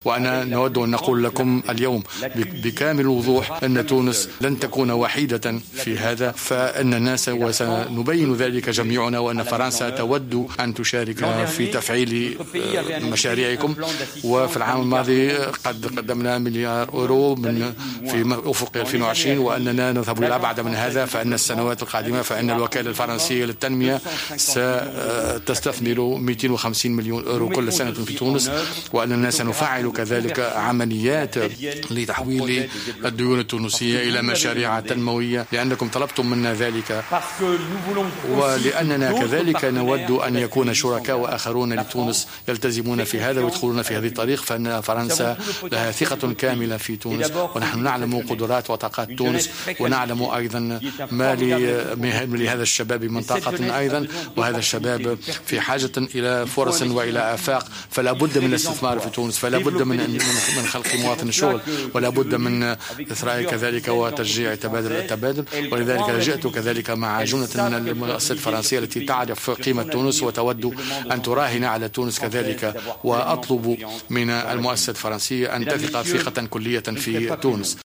أكد الوزير الأول الفرنسي مانويل فالس في افتتاح مؤتمر دعم الاقتصاد والاستثمار "تونس 2020" أن فرنسا قدّمت مساعدات بقيمة مليار أورو لتونس على مدى 5 سنوات.